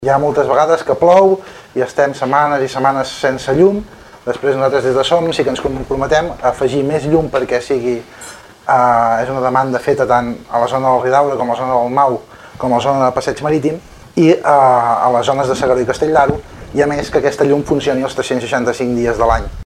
El debat electoral Castell-Platja d’Aro 2019 s’ha emès a Ràdio Capital aquest dimarts al vespre amb la presència de tots els candidats a l’alcaldia.